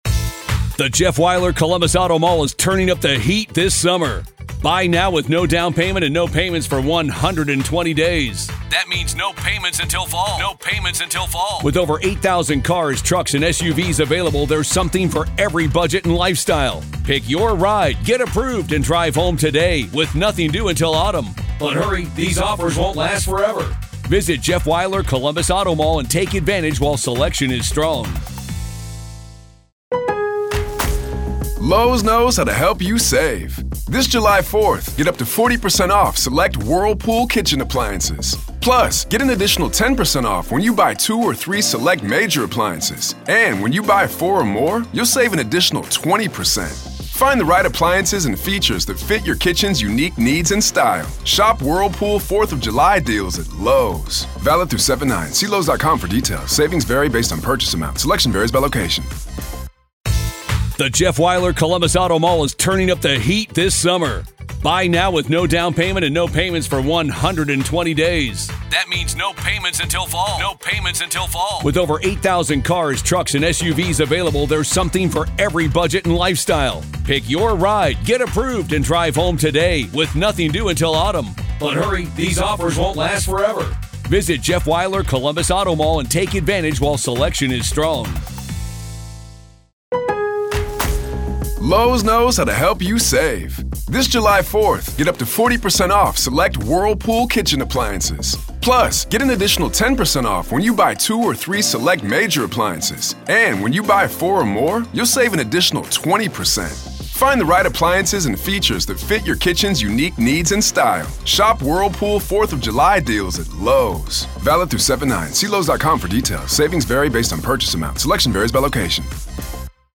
Whether you are a dedicated follower of true crime, or an everyday listener interested in the stories shaping our world, the "Week in Review" brings you the perfect balance of intrigue, information, and intelligent conversation.